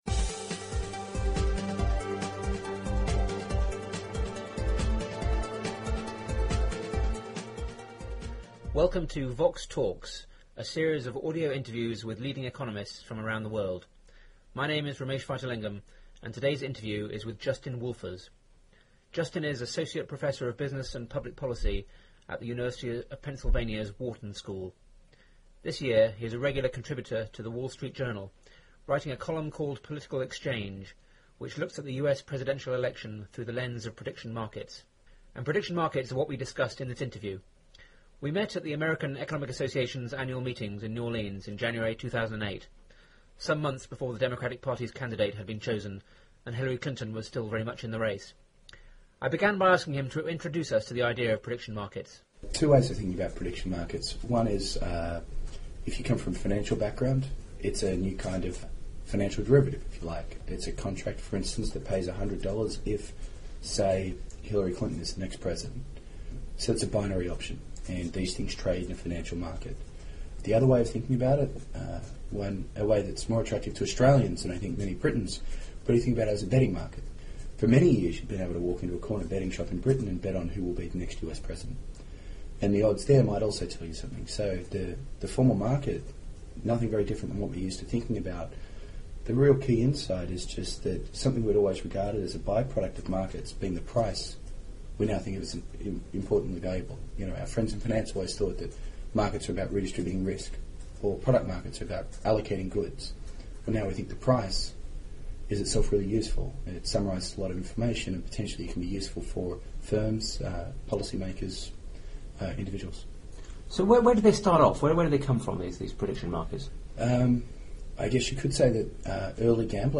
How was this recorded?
The interview was recorded at the American Economic Association meetings in New Orleans in January 2008.